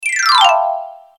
Marimba-descending-arpeggio-swoosh-sound-effect.mp3